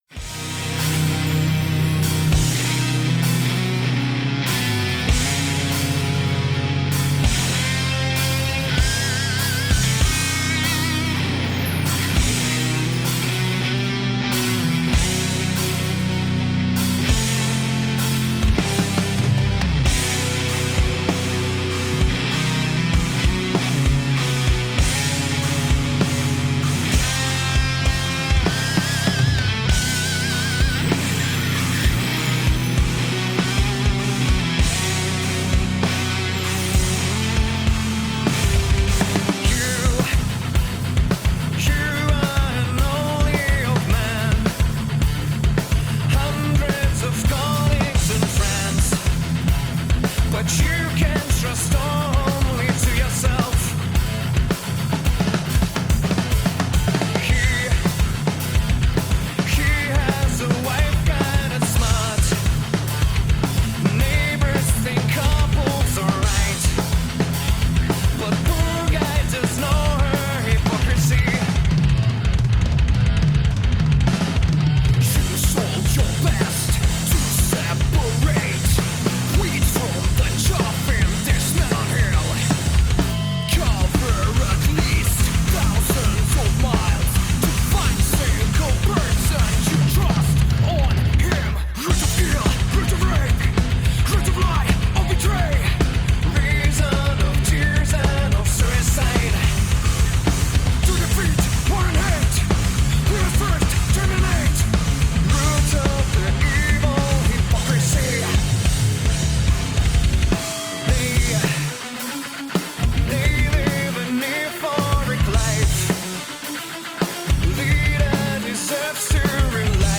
Heavy metal, male vocals. 2.2 MB.